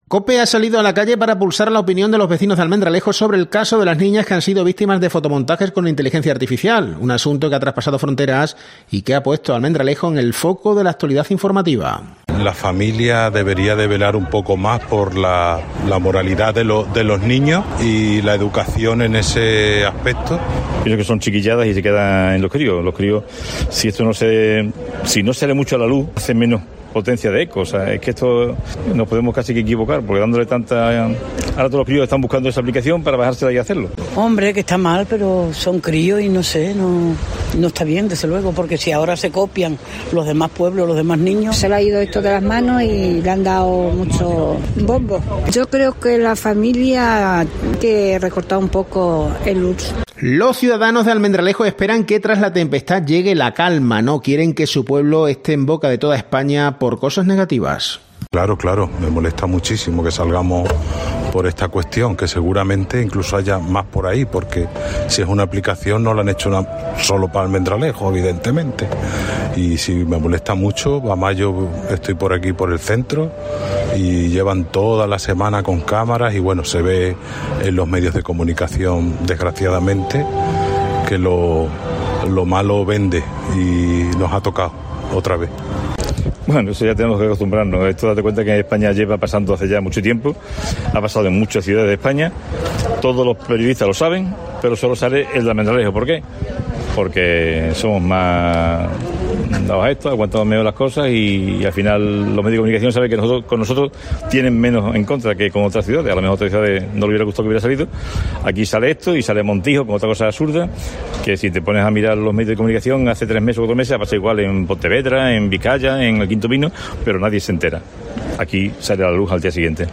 COPE ha salido a la calle para pulsar la opinión de los almendralejenses sobre el caso de las niñas que han sido víctimas de fotomontajes con Inteligencia Artificial.
Son opiniones de los vecinos que no paran de hablar estos días de un tema en el que confluyen el mal uso de la tecnología por parte de los niños y el papel que juegan los padres en el mundo en el que vivimos.